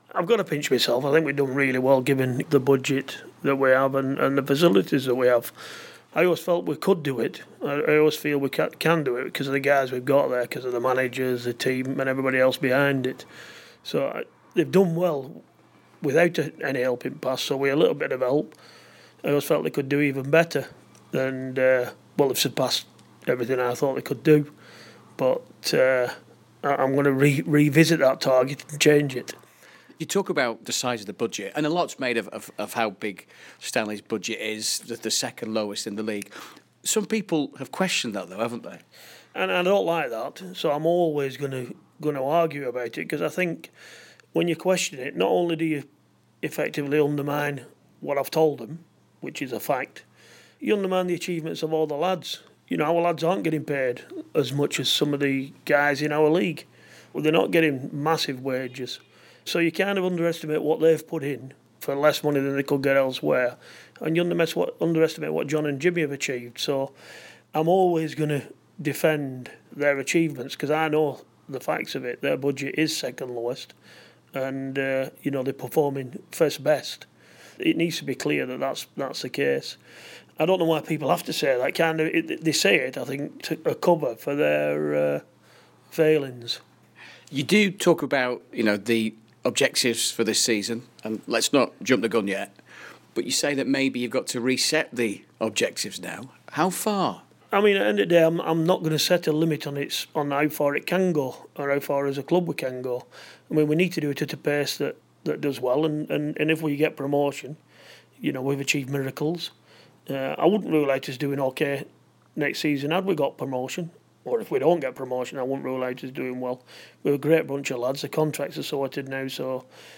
An episode by BBC Lancashire Sport